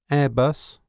a_airbus.wav